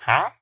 haggle1.ogg